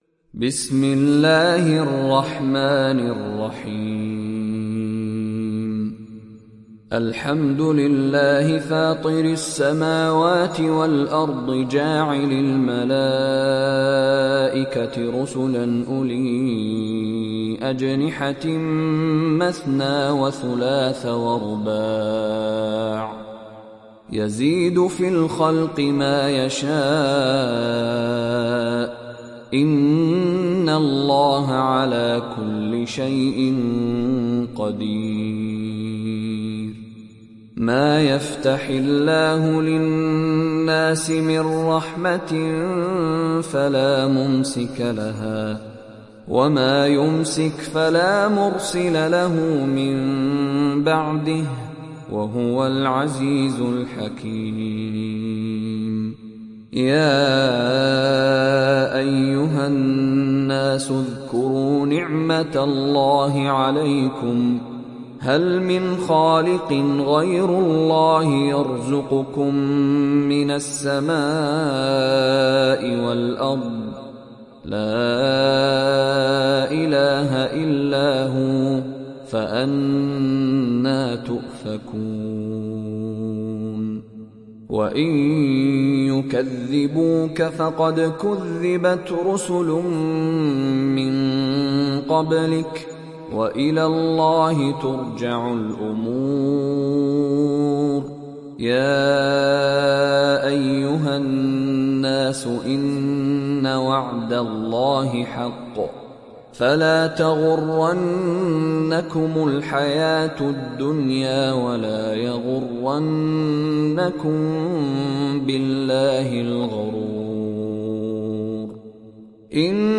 Surat Fatir Download mp3 Mishary Rashid Alafasy Riwayat Hafs dari Asim, Download Quran dan mendengarkan mp3 tautan langsung penuh